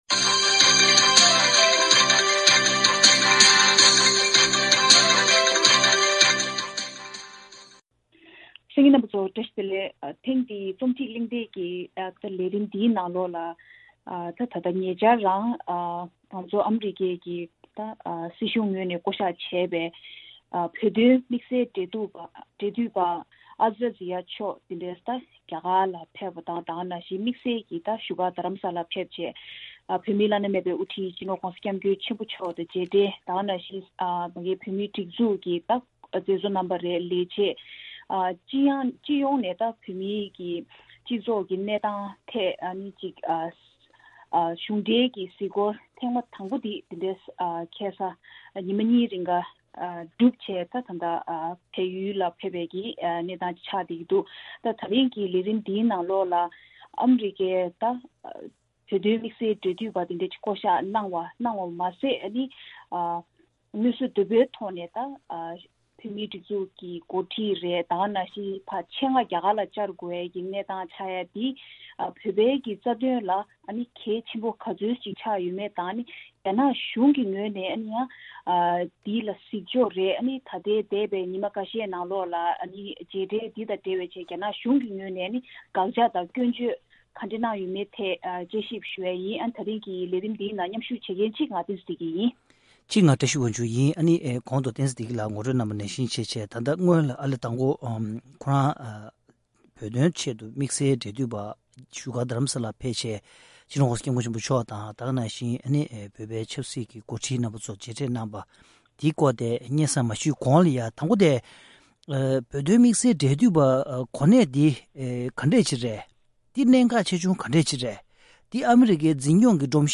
ཐེངས་འདིའི་རྩོམ་སྒྲིག་པའི་གླེང་སྟེགས་ཀྱི་ལས་རིམ་ནང་།